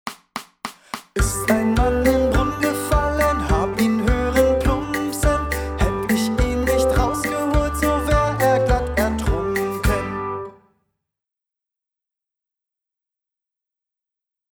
schnell